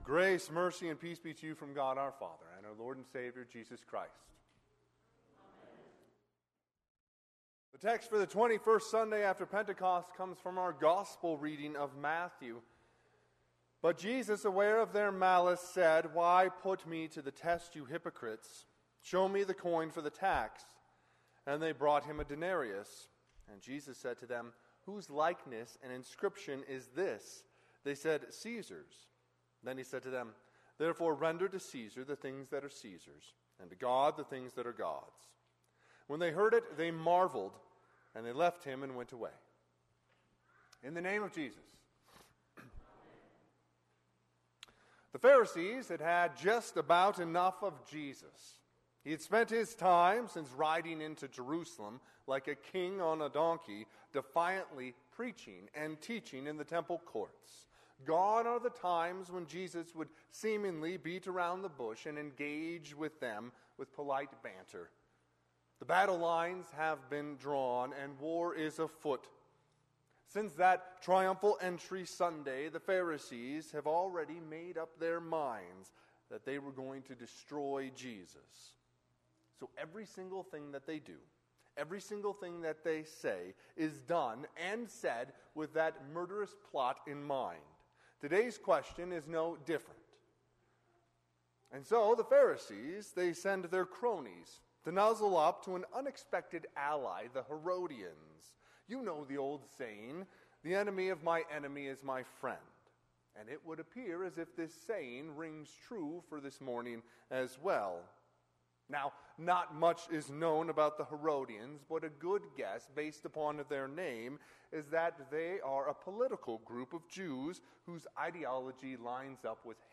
Sermon - 10/22/2023 - Wheat Ridge Lutheran Church, Wheat Ridge, Colorado
Twenty-First Sunday after Pentecost